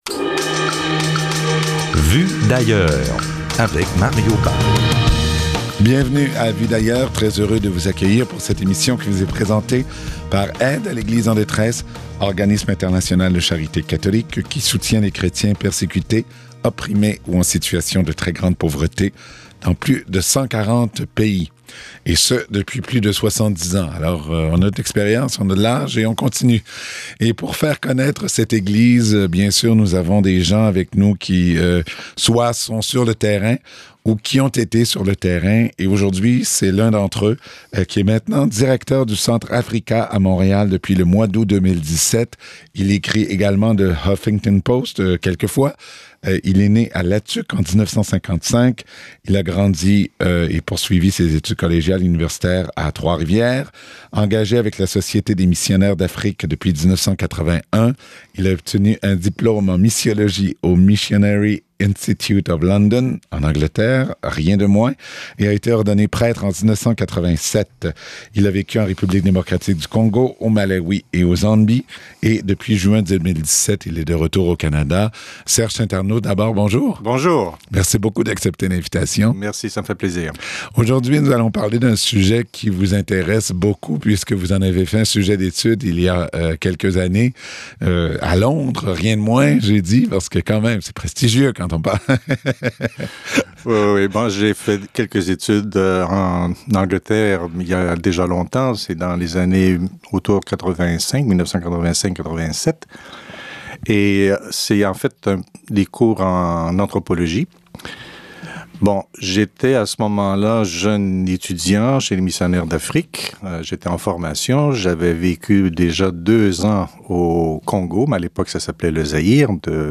Cliquer sur le lien audio suivant pour écouter l’émission.